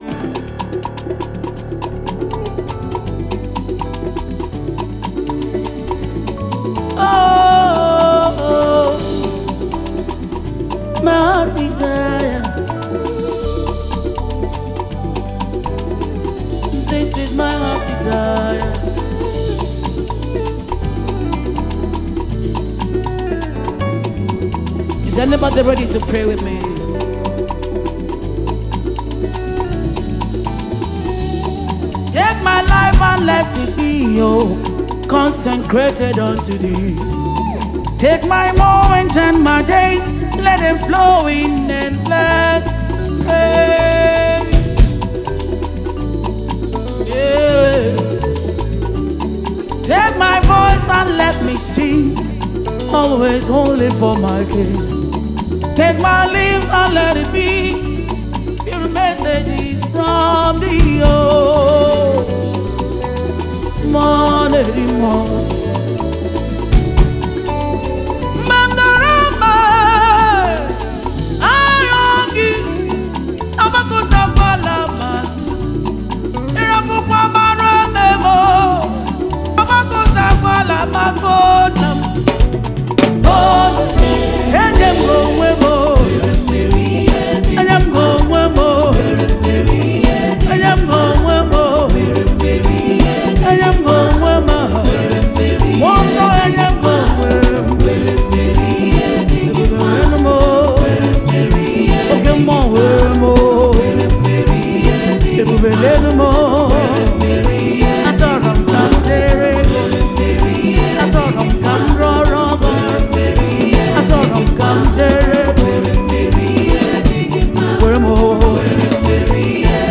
Nigerian gospel music powerhouse